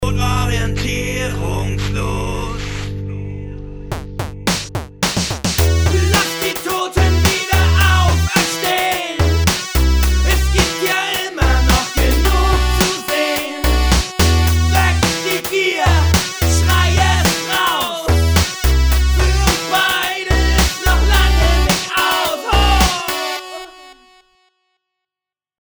Gameboy-Musik.